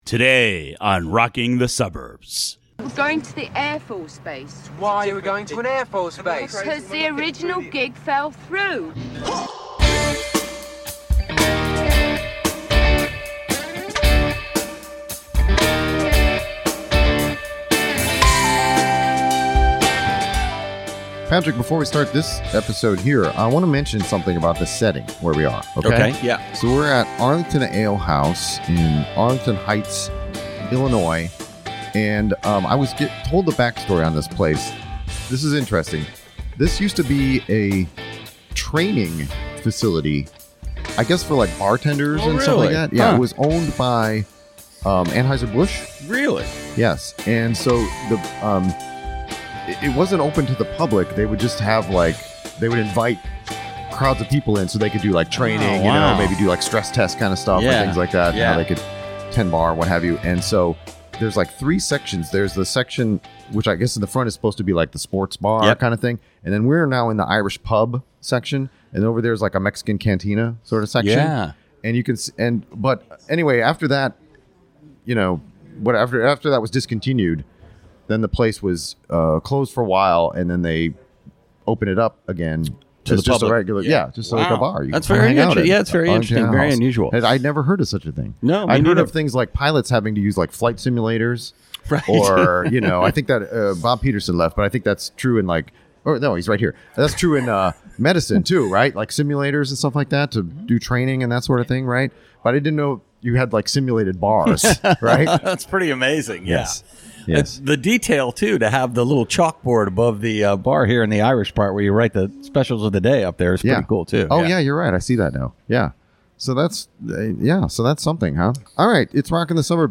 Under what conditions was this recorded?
It's the third of the recordings we made on Day Two of Suburbs Fest Midwest! A crowd gathered at Arlington Ale House to join us and contribute.